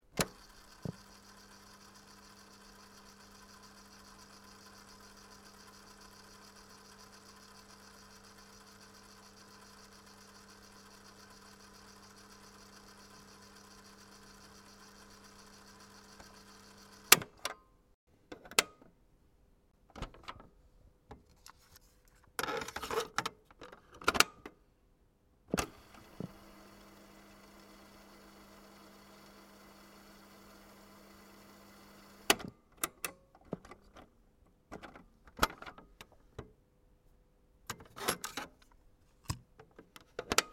Sony CFS-W3045 radio cassette recorder